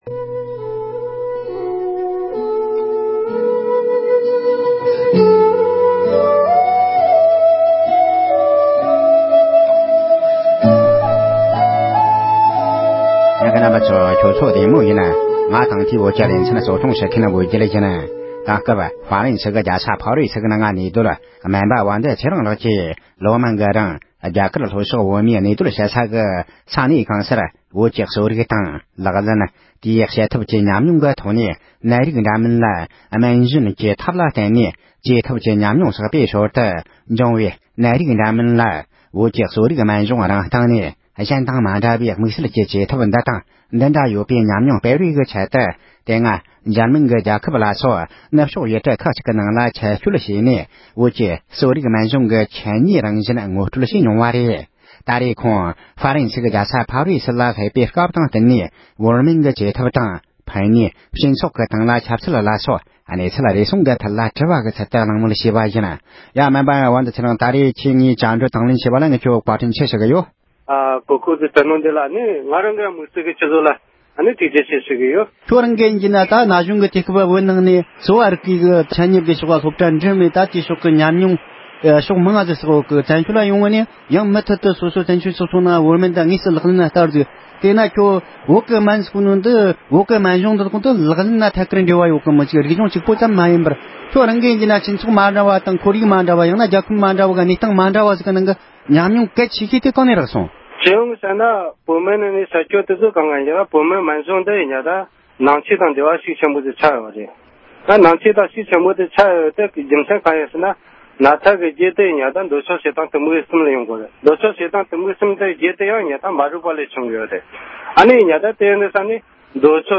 བོད་ཀྱི་གསོ་རིག་དང་ལག་ལེན་་དེ་བཞིན་བྱེད་ཐབས་བཅས་ཀྱི་སྐོར་གླེང་མོལ།